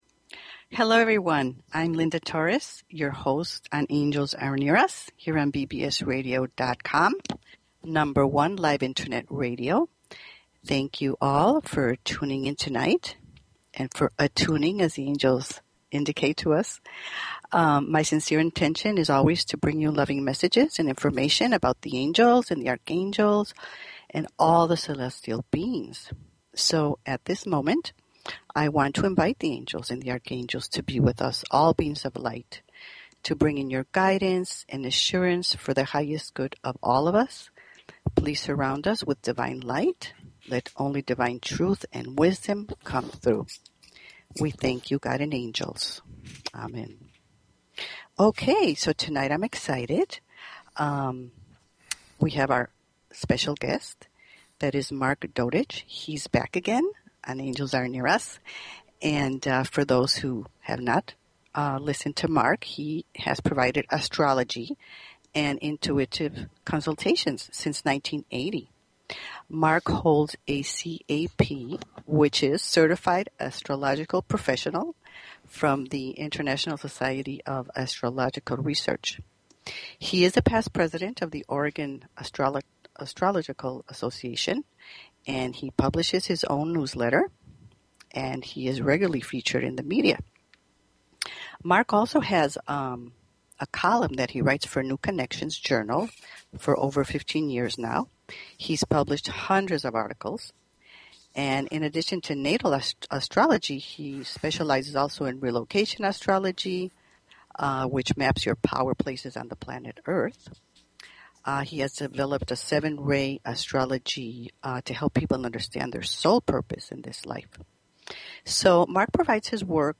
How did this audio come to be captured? The last 30 minutes of the show the phone lines will be open for questions and Angel readings.